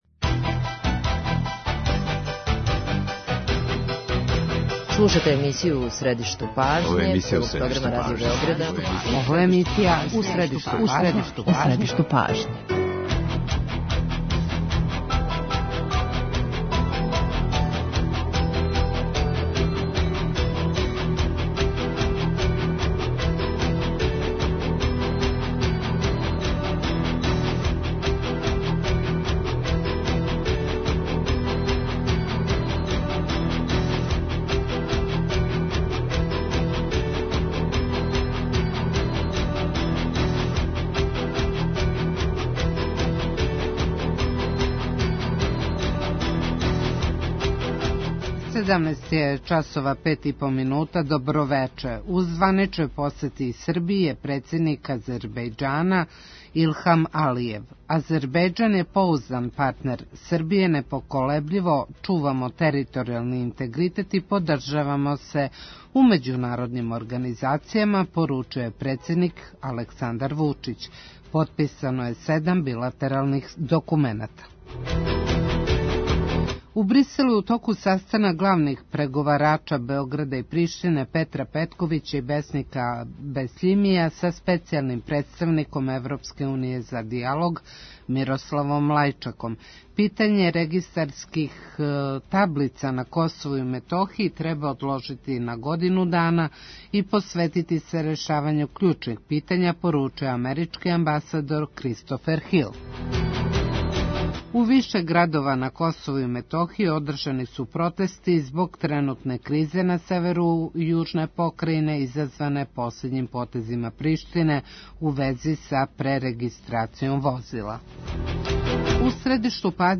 Гост емисије је Павле Петровић, председник Фискалног савета.